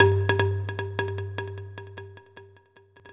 Marimba.wav